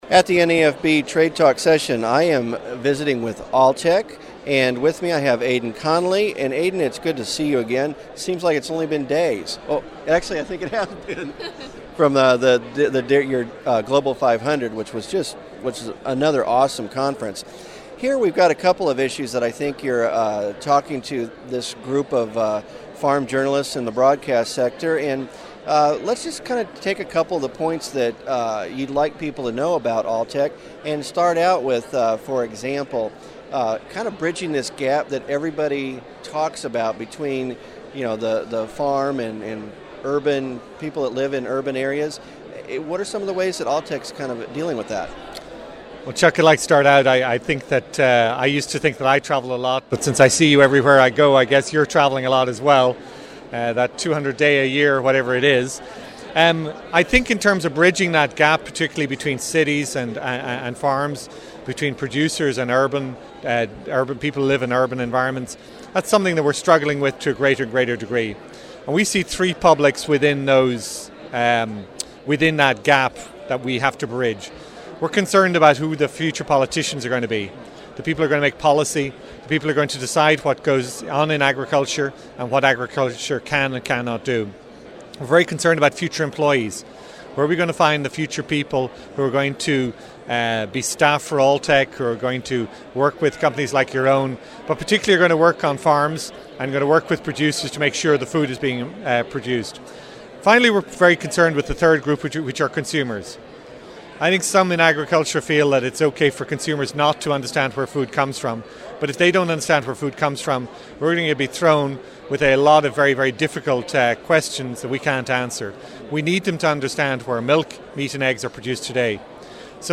Alltech Interview